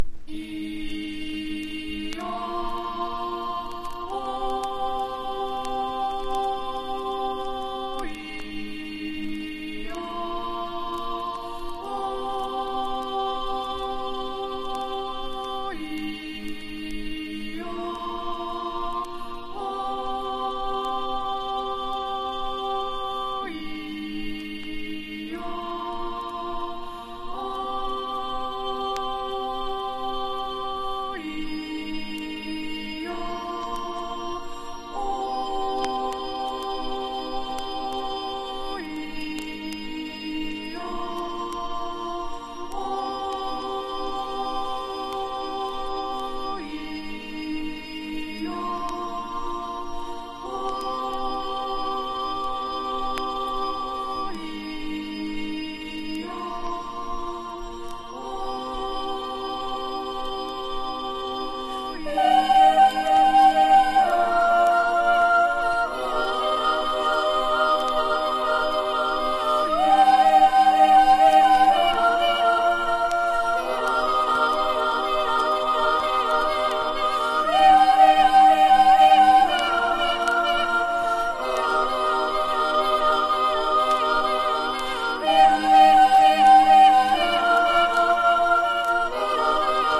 AMBIENT / EXPERIMENTAL# 70’s ROCK# 現代音楽